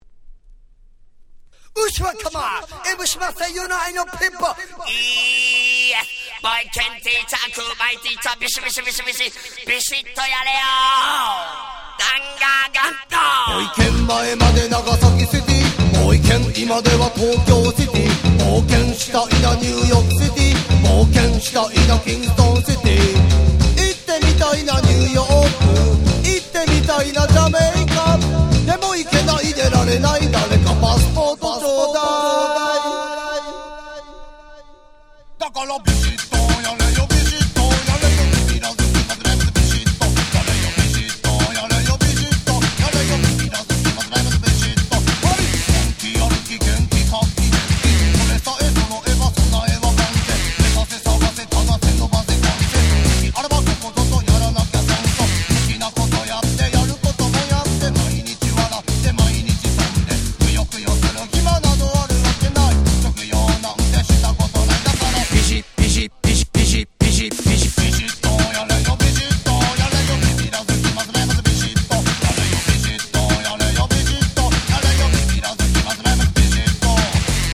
91' Japanese Reggae Classics !!